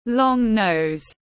Mũi dài tiếng anh gọi là long nose, phiên âm tiếng anh đọc là /lɒŋ nəʊz/ .
Long nose /lɒŋ nəʊz/